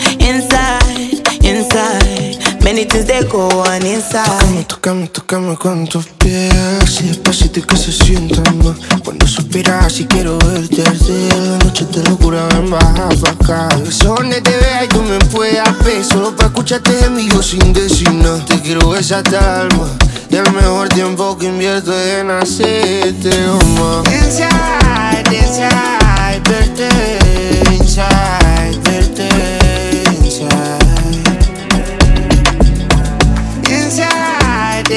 Жанр: Поп / Африканская музыка